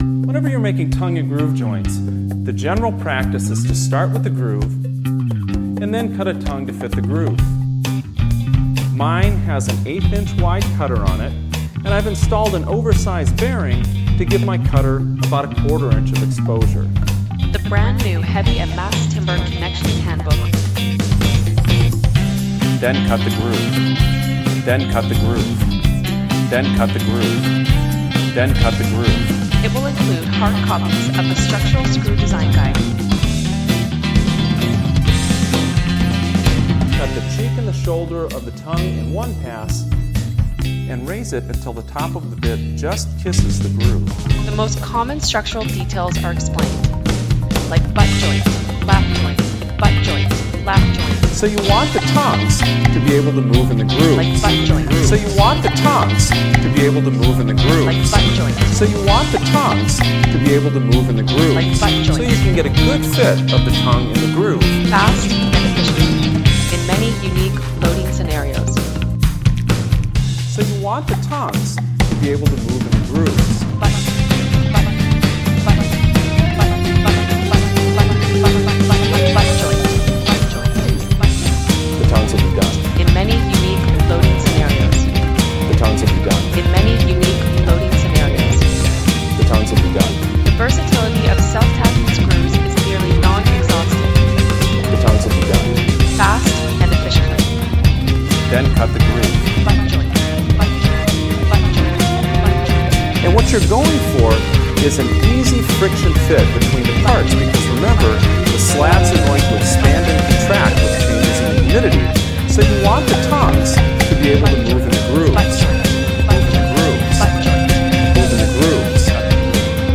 Is that a bit of woodblock in the background?